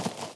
default_grass_footstep.1.ogg